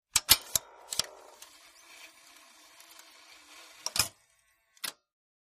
3/4" Video tape deck is loaded. Tape Loading Transport Engage